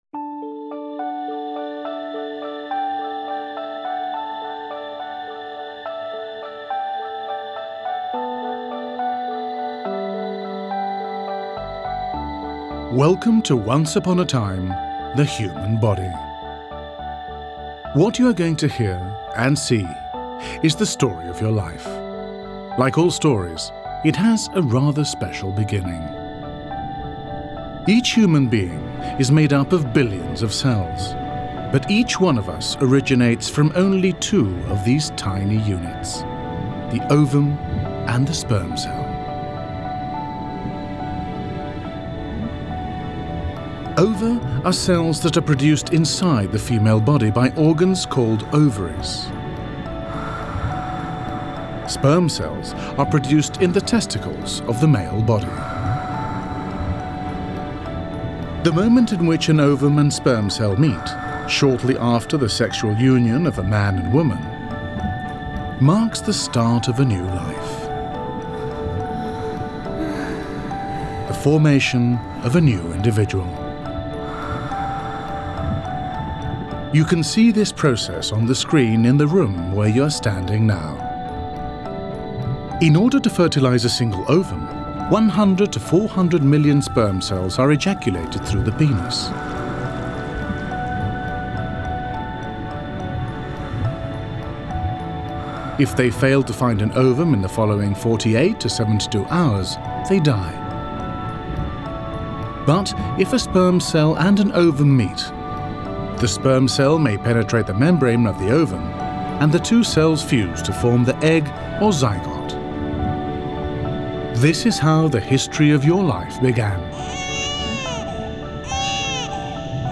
• Podcast/Audioguías